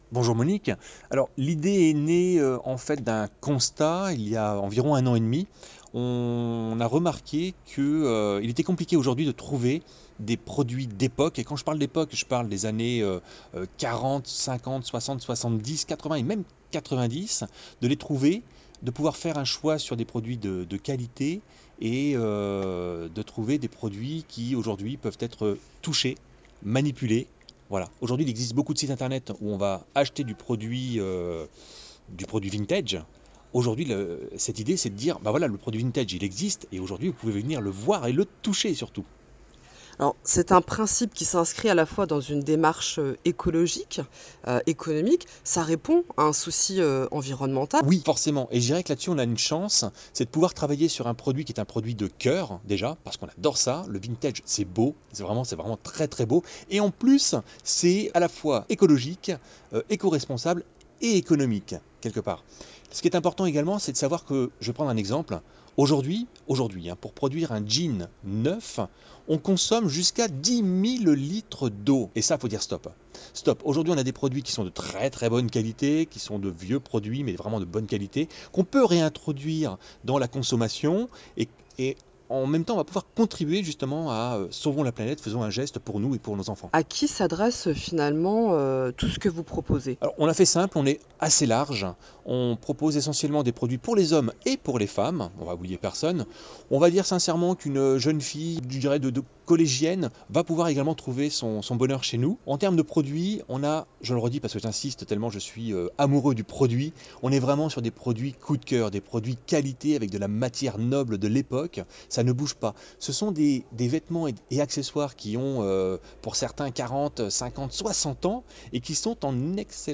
Interview Vintage EcoShop.wav (15.95 Mo)